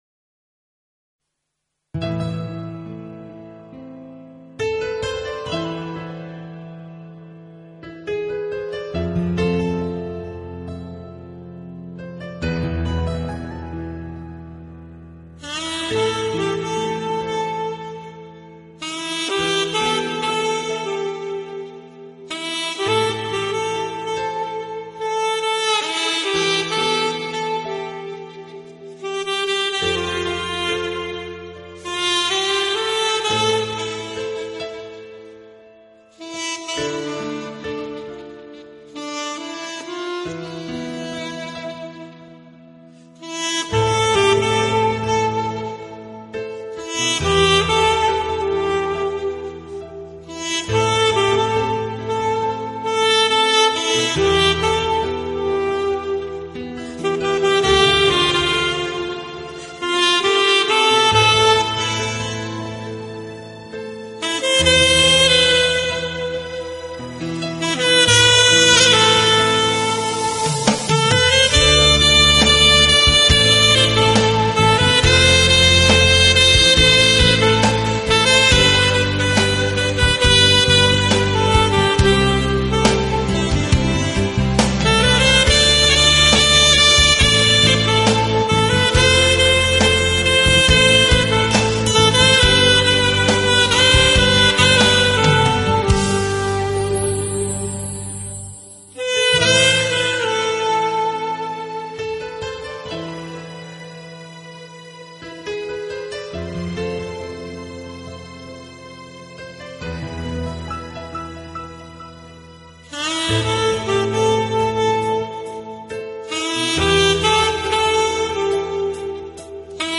萨克斯象征着一种欲语还休的心情，象征着一种深澈入骨的关爱。
音乐类型：POP